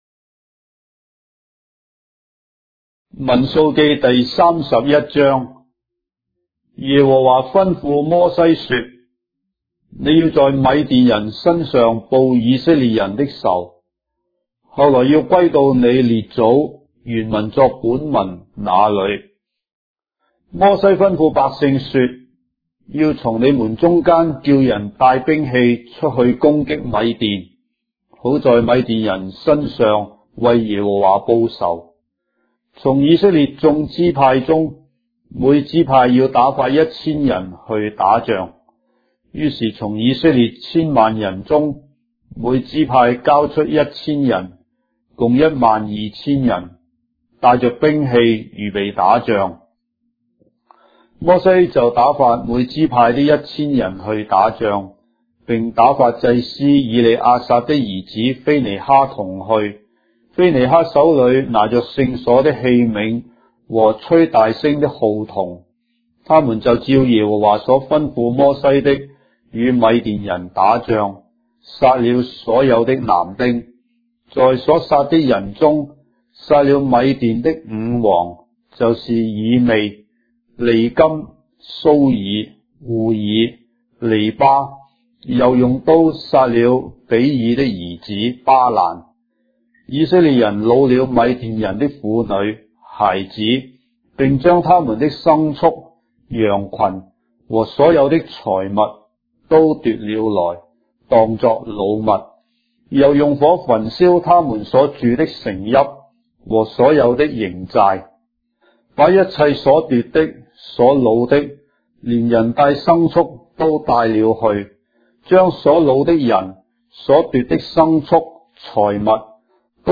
章的聖經在中國的語言，音頻旁白- Numbers, chapter 31 of the Holy Bible in Traditional Chinese